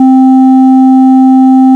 TRIANG A triangle wave. Another "Classic" analogue waveform.
triang.au